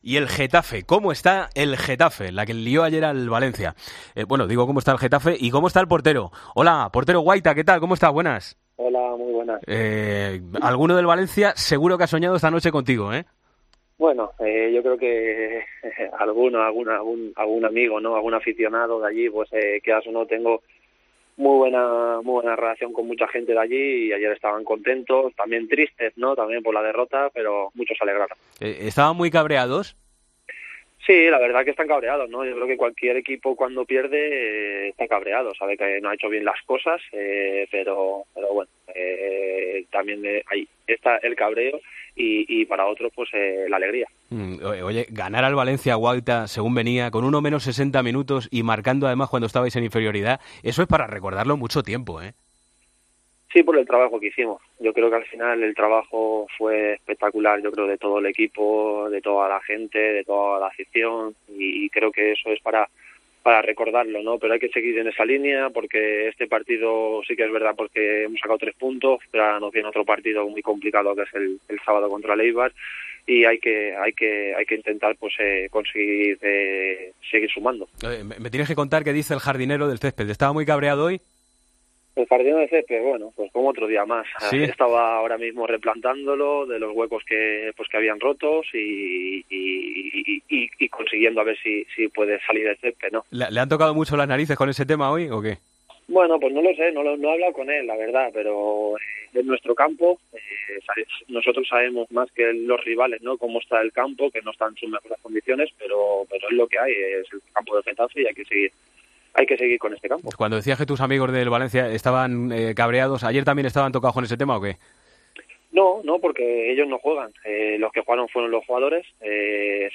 Hablamos con el portero del Getafe tras la victoria frente al Valencia: "Todos hicimos un gran trabajo y es para recordarlo.